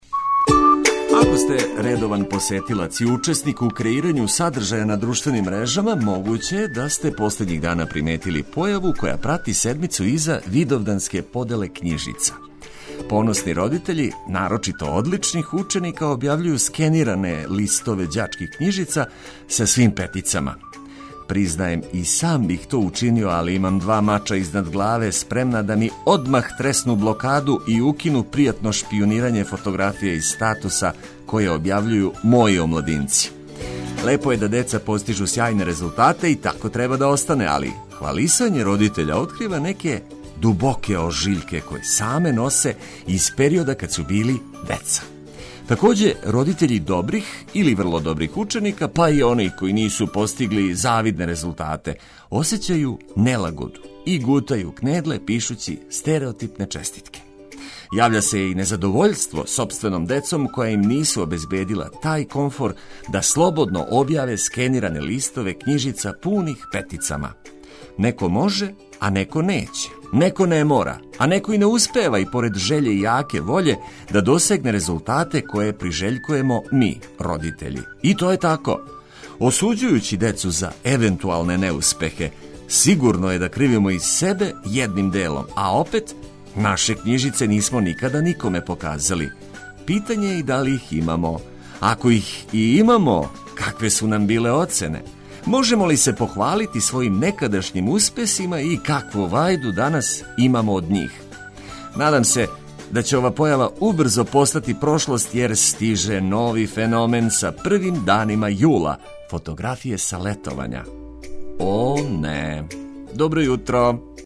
Vreli talas nam je malo usporio radni ritam, ali uz letnje hitove i korisne informacije, lakše ćemo kročiti u novi dan.